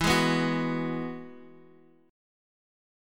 Eadd9 Chord
Listen to Eadd9 strummed